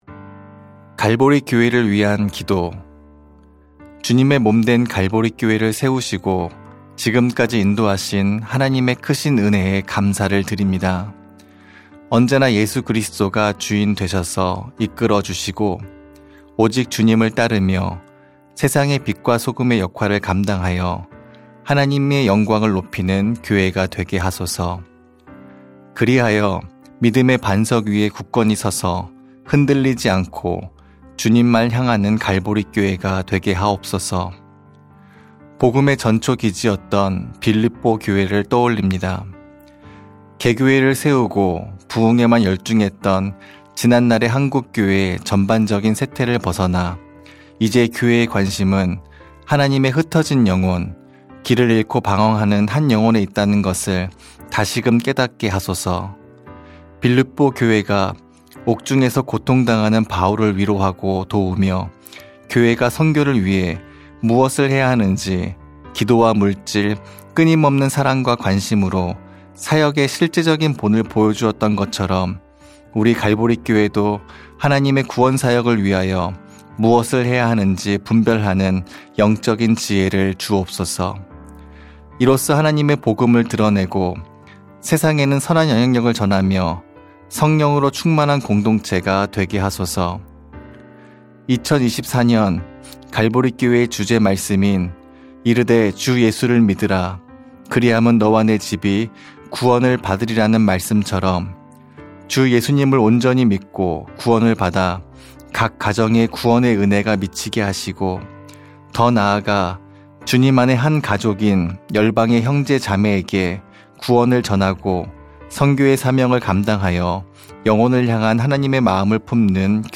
중보기도